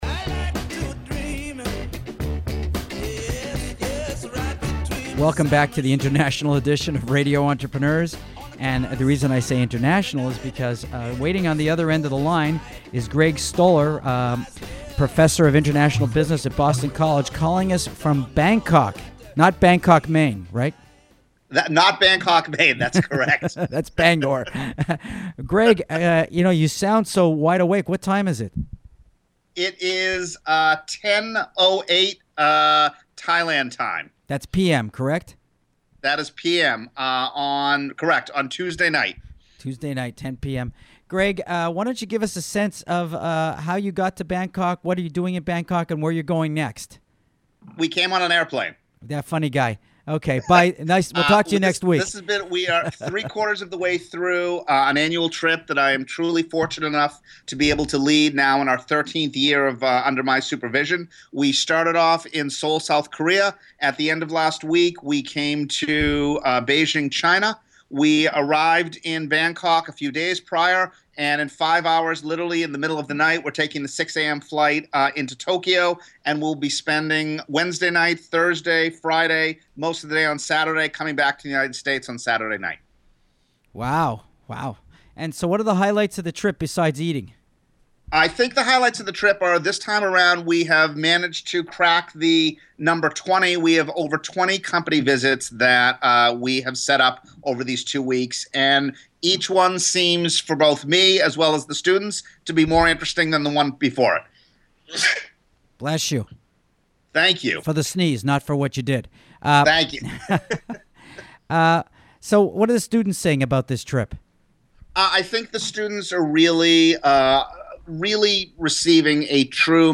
Here’s a 10-minute interview about IME Asia from Radio Entrepreneurs.